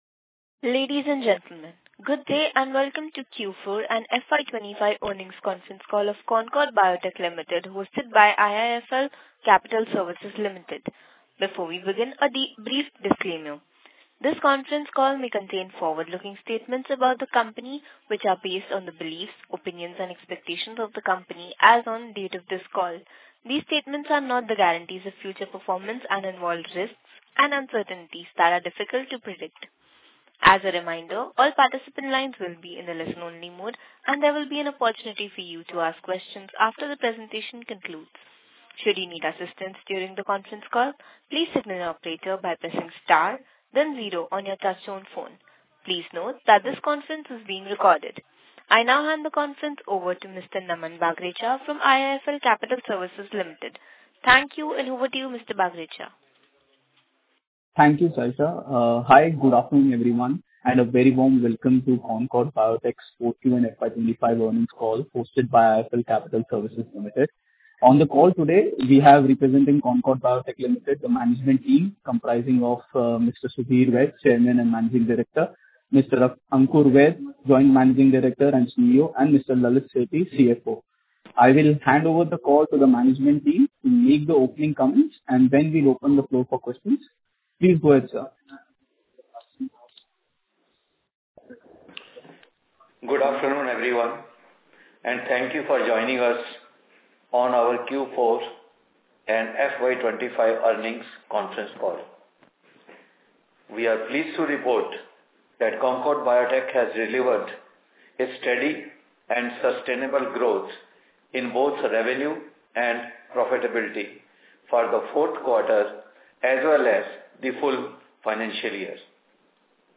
Concalls
concord-biotech-Q4FY25-call-audio.mp3